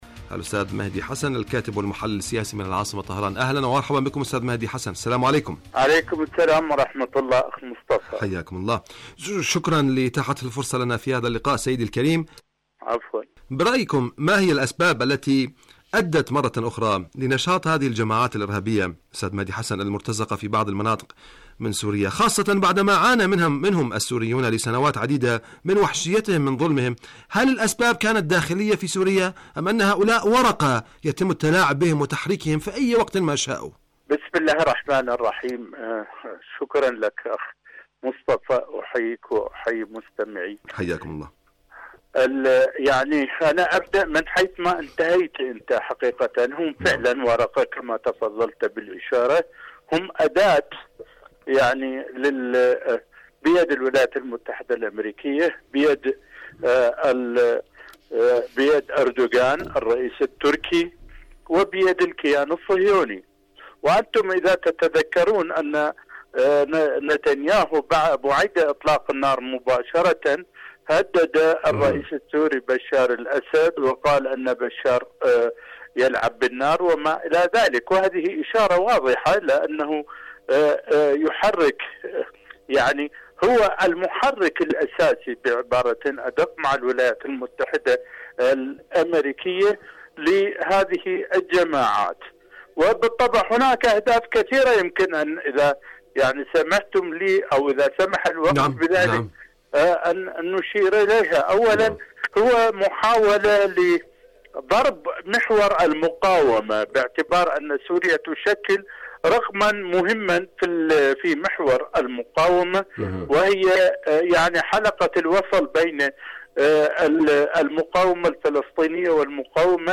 مقابلات برامج إذاعة طهران العربية برنامج حدث وحوار مقابلات إذاعية غزة سوريا معركة سورية حرب سوريا الكيان الغاصب العدو المجرم خلق الأوراق في سوريا، من المستفيد؟